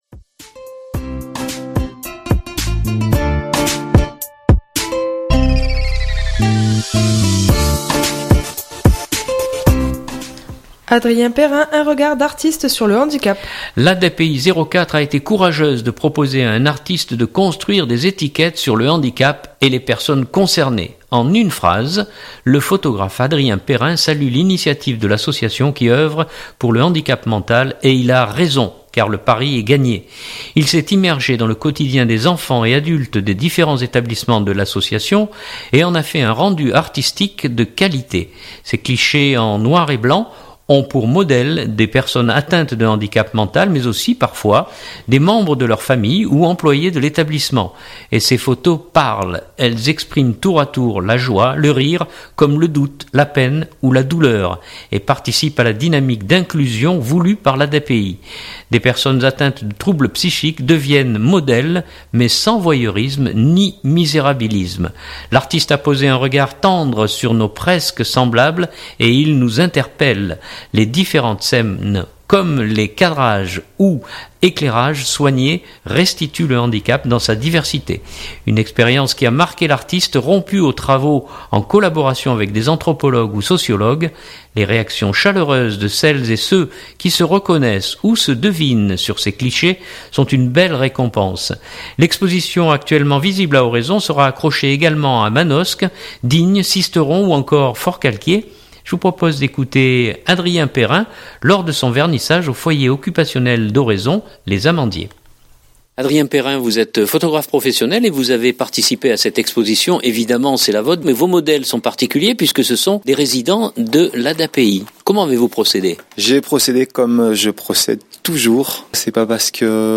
lors de son vernissage au foyer occupationnel d’Oraison « Les Amandiers ».